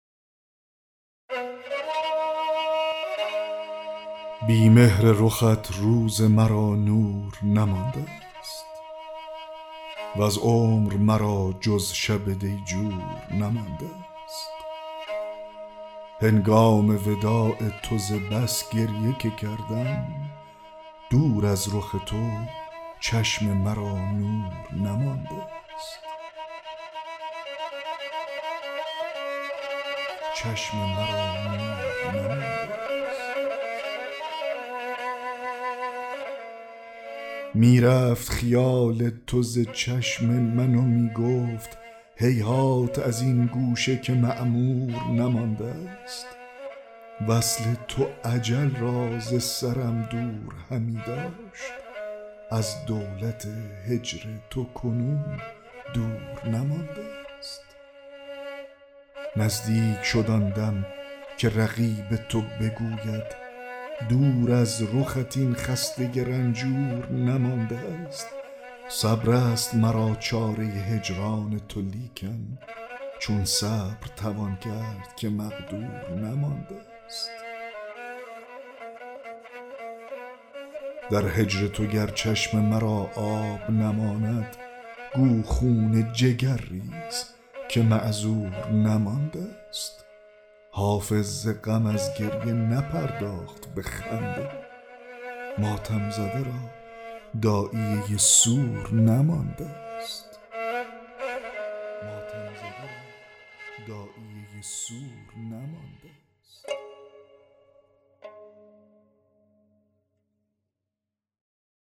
دکلمه غزل 38 حافظ
دکلمه-غزل-38-حافظ-بی-مهر-رخت-روز-مرا-نور-نماندست.mp3